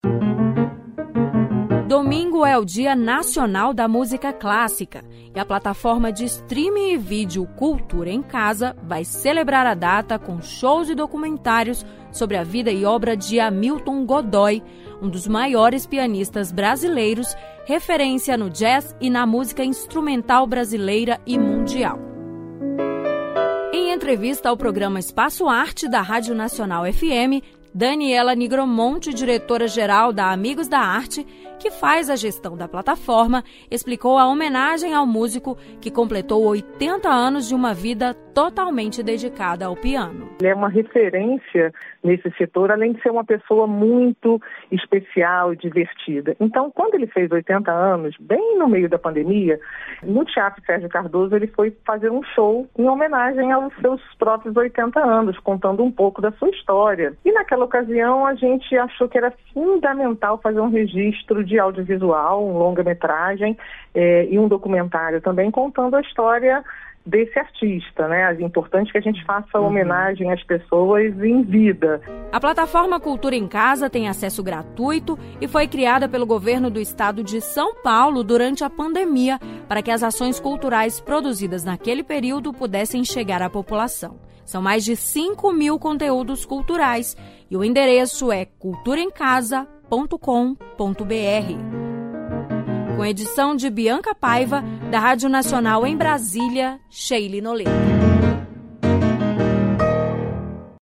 Em entrevista ao programa Espaço Arte, da Rádio Nacional FM